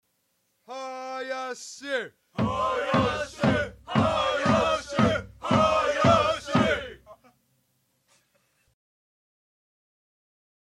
選手のコール
基本は「選手苗字＋（ドンドンドン）」（太鼓に合わせて手拍子）。